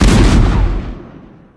rose_rocket_ulti_explo_01.wav